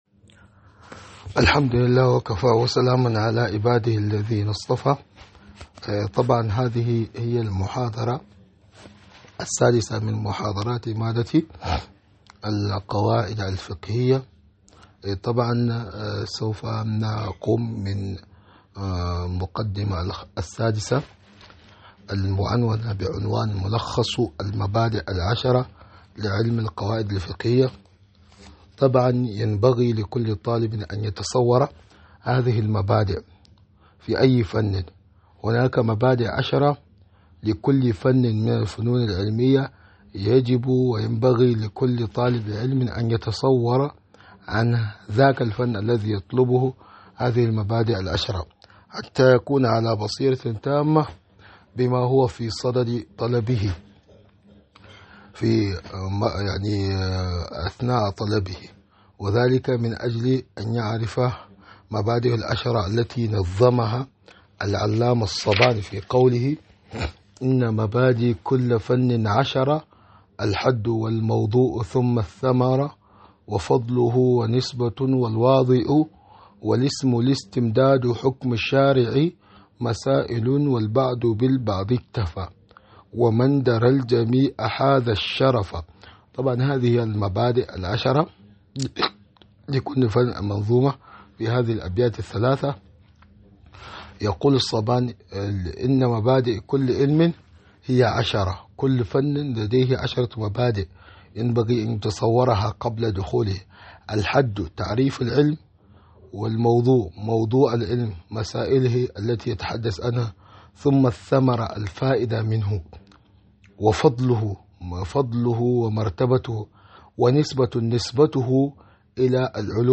محاضرة مادة القواعد الفقهية 006